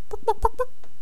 chicken_select1.wav